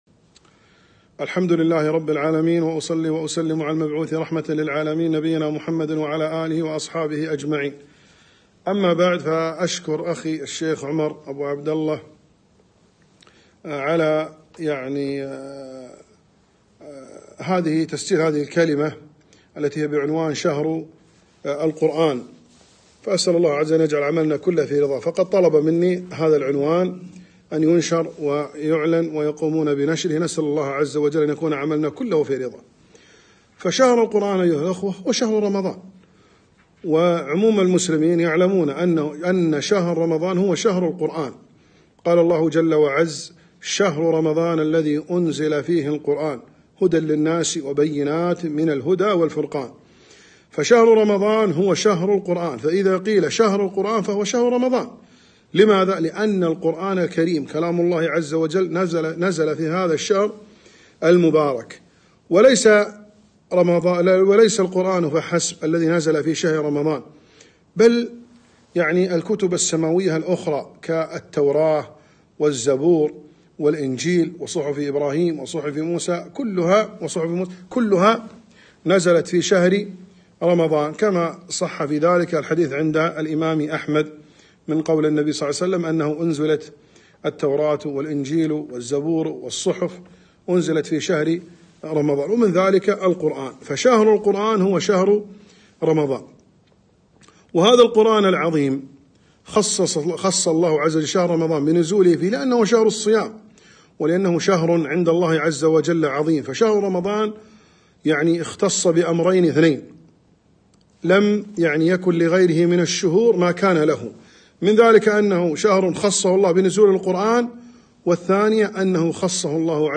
محاضرة - شهر القران